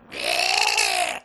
Goblin_Death3.wav